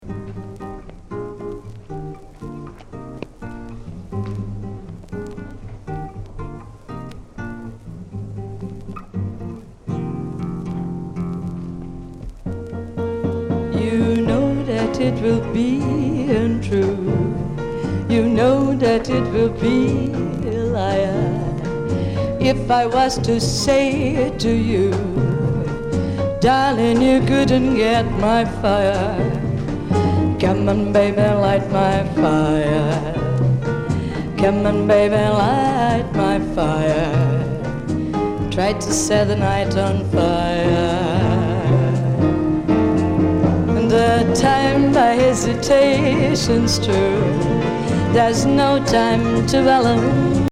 ’69年リオでのライブ録音